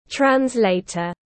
Phiên dịch viên tiếng anh gọi là translator, phiên âm tiếng anh đọc là /trænzˈleɪtər/.
Translator /trænzˈleɪtər/
Translator.mp3